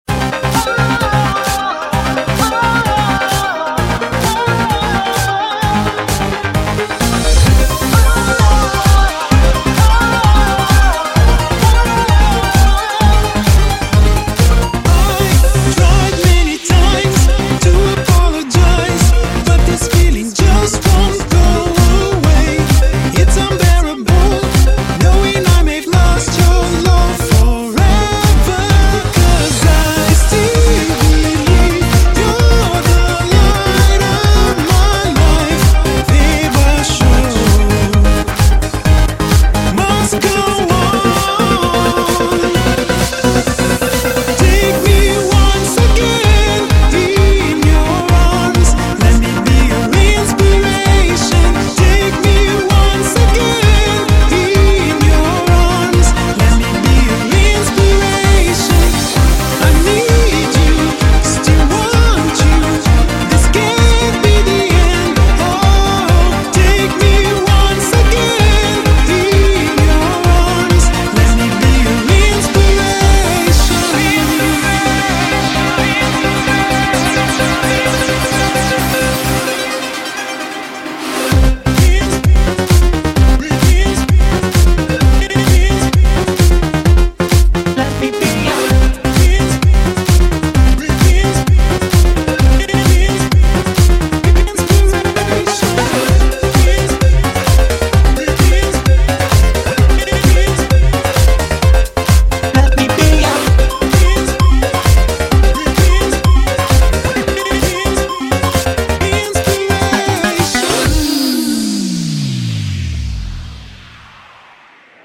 BPM130-130
Audio QualityMusic Cut